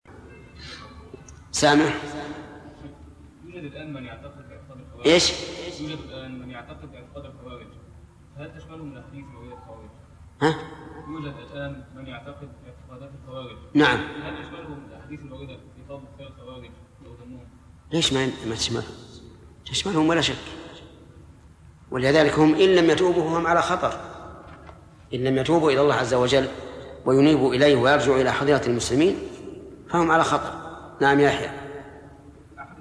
Format: MP3 Mono 22kHz 64Kbps (CBR)